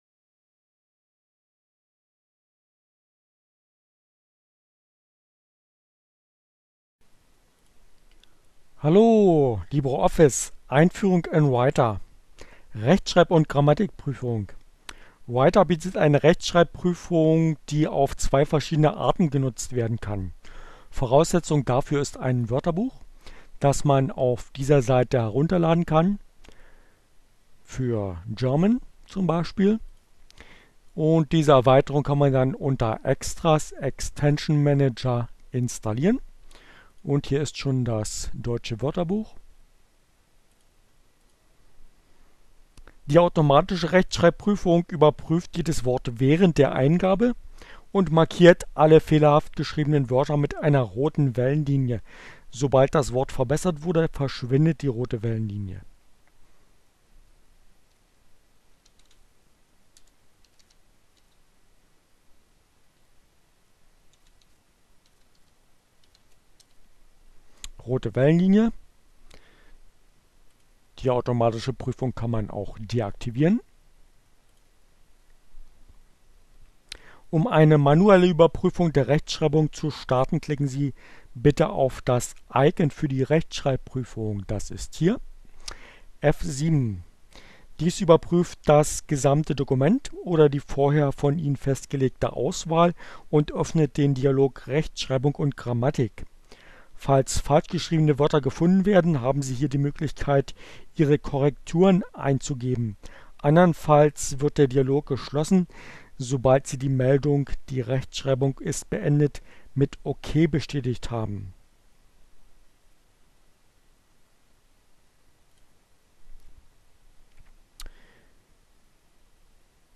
Tags: Fedora, Gnome, Linux, Neueinsteiger, Ogg Theora, ohne Musik, screencast, CC by, LibreOffice, Gnome3